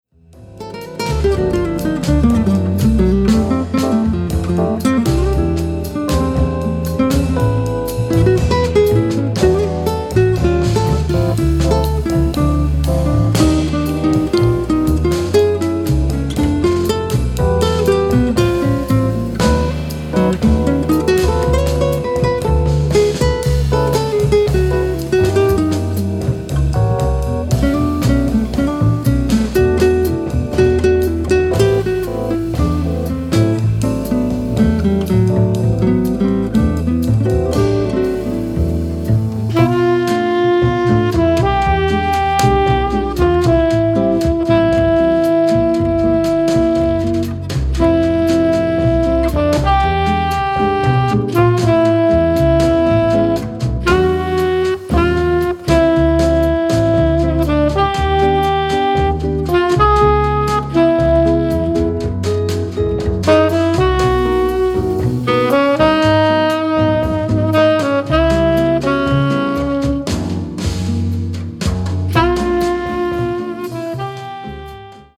Live Jazz in all its forms for any occasion
Instrumental